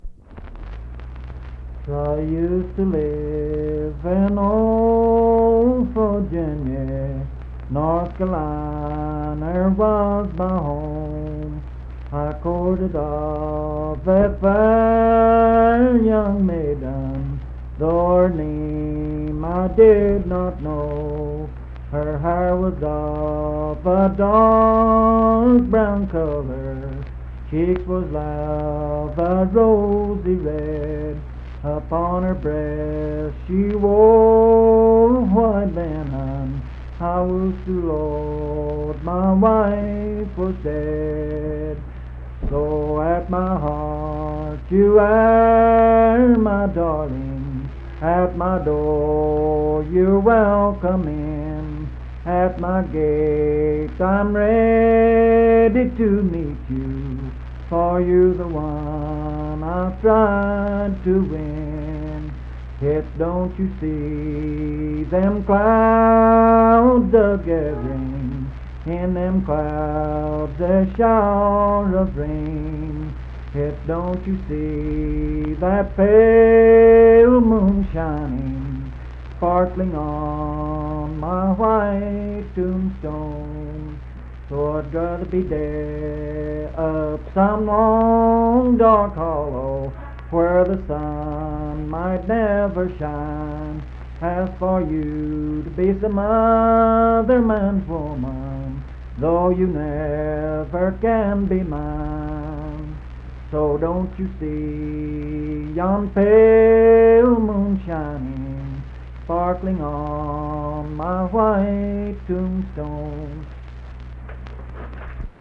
Unaccompanied vocal music
Verse-refrain 6(4w/R).
Voice (sung)